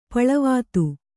♪ paḷavātu